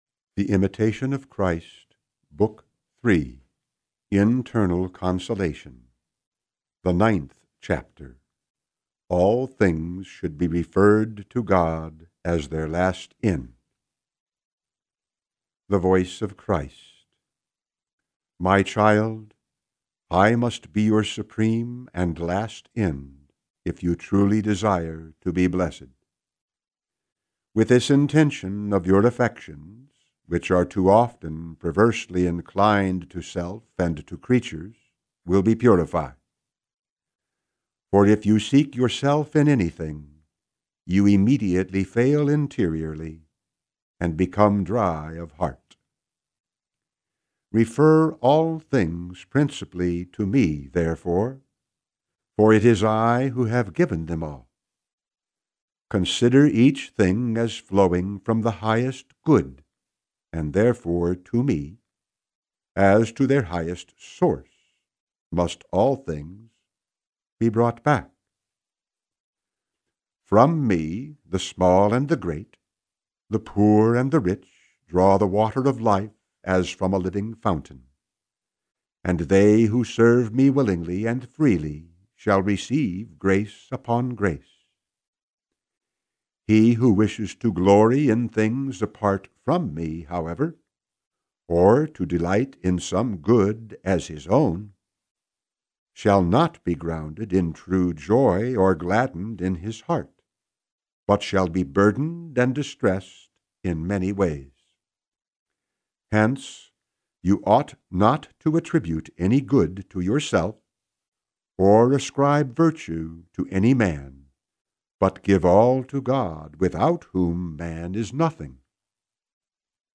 Genre: Spiritual Reading.